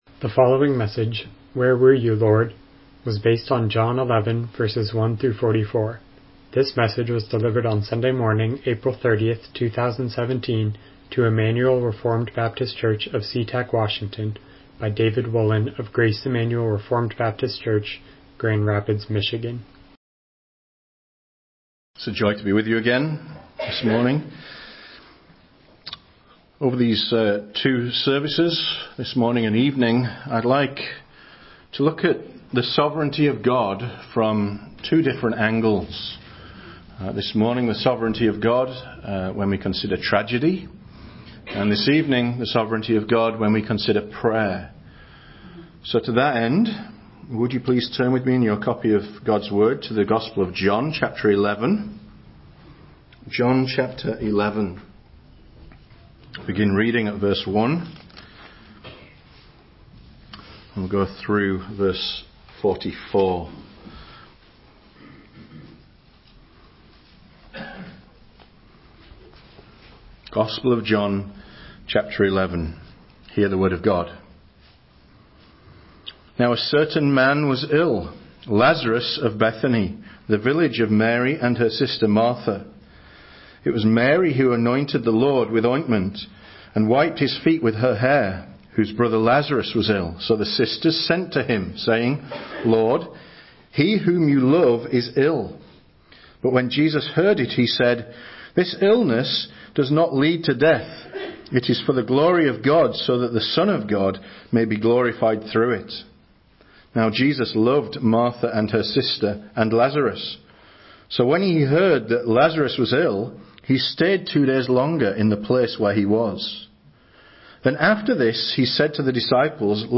Miscellaneous Passage: John 11:1-44 Service Type: Morning Worship « John Huss and the Hussites If God Already Knows